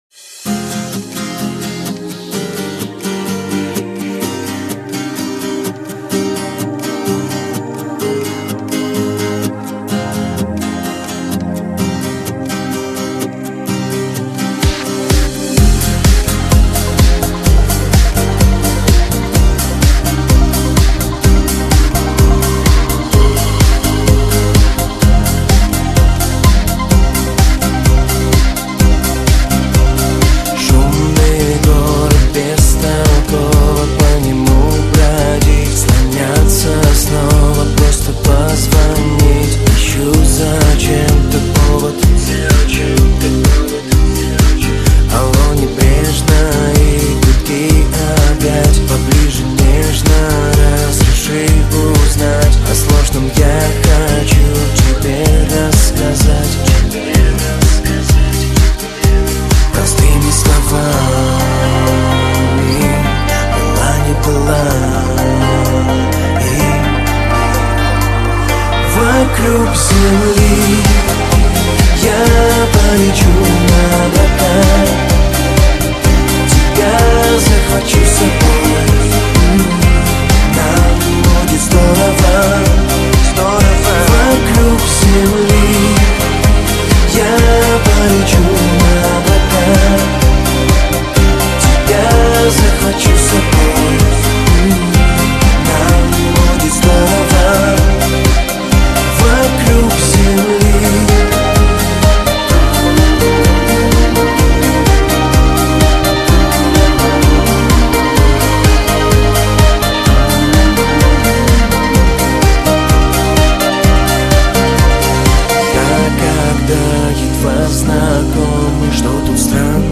Категория: Танцевальная/Послушать